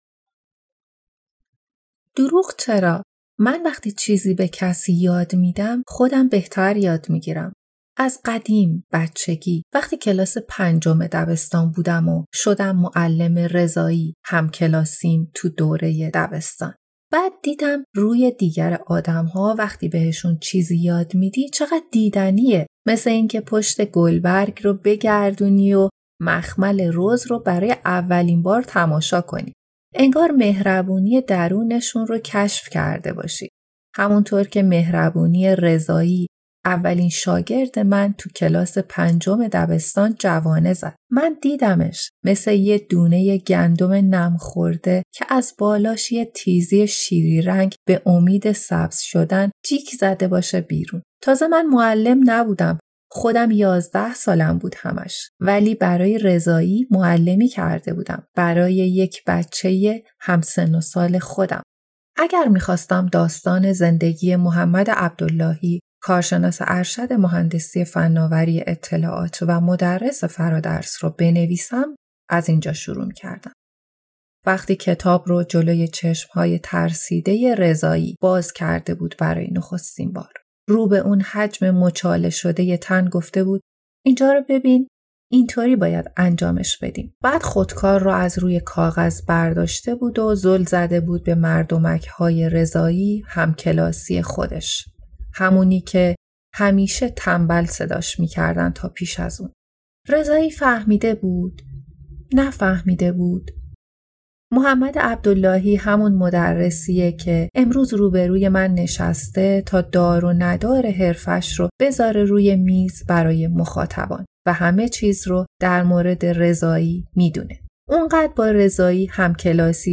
مصاحبه
نسخه صوتی مقدمه